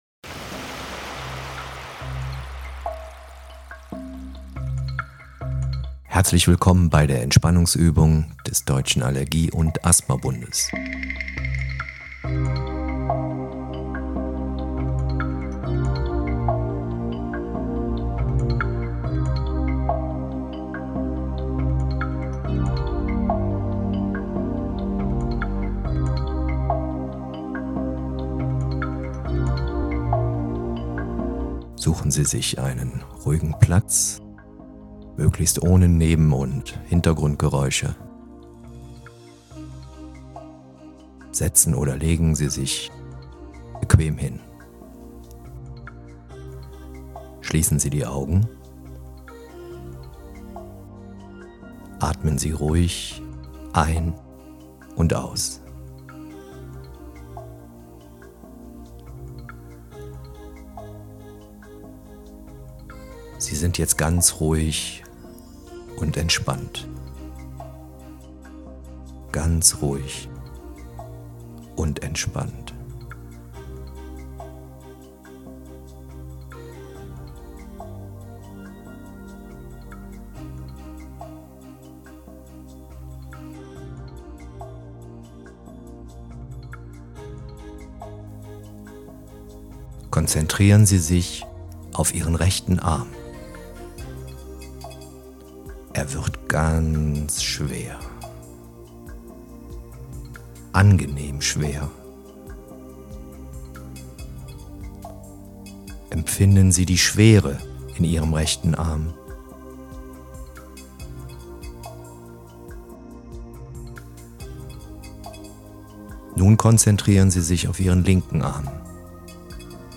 DAAB_Entspannunguebung.mp3